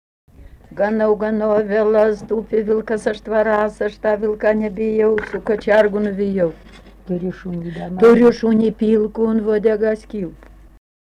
smulkieji žanrai
Melninkai
vokalinis